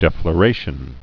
(dĕflə-rāshən)